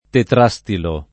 [ tetr #S tilo ]